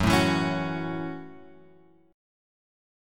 F# Minor 7th